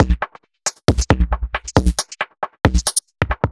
Index of /musicradar/uk-garage-samples/136bpm Lines n Loops/Beats
GA_BeatFilterB136-07.wav